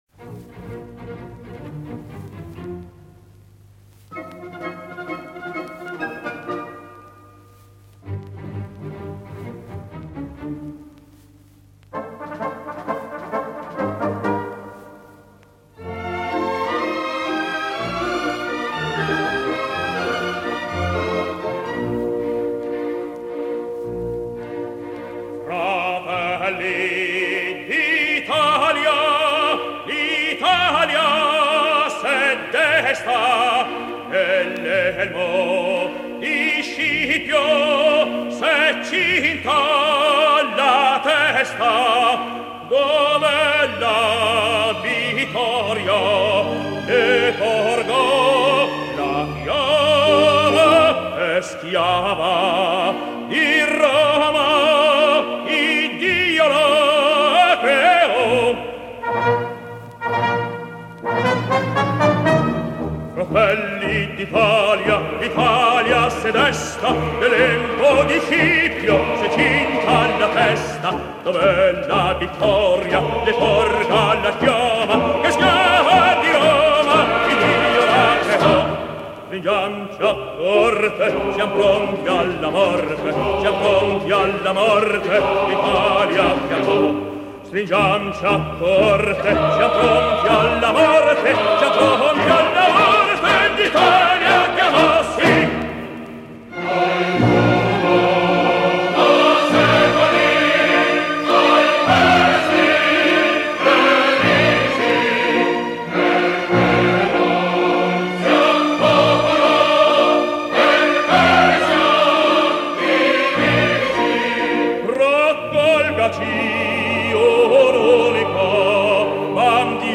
|thumb|마리오 델 모나코가 1961년에 부른 버전
미켈레 노바로가 작곡한 "일 칸토 델리 이탈리아니"(이탈리아인의 노래)는 전형적인 행진곡풍의 4/4 박자 곡으로, 내림 나장조이다.[4] 기억하기 쉬운 선율리듬을 가지고 있어 대중에게 쉽게 전파되었다.[4] 화성과 리듬은 더 복잡한 구성을 보인다.